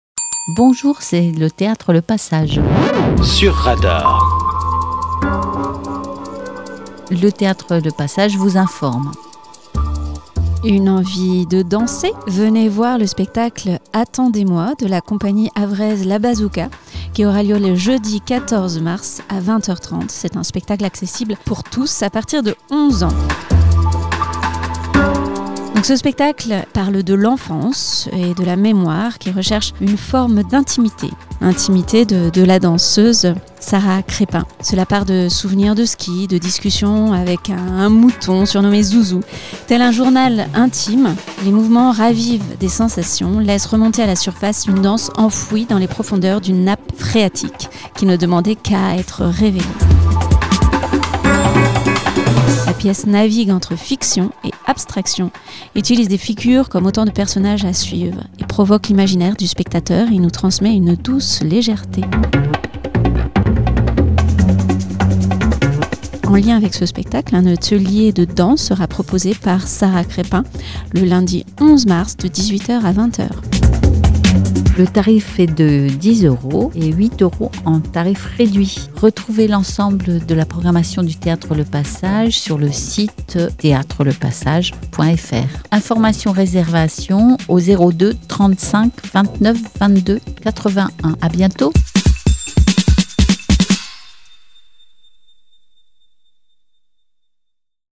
Régulièrement, différentes associations Fécampoises viennent dans nos studios pour enregistrer leurs différentes annonces pour vous informer de leurs activités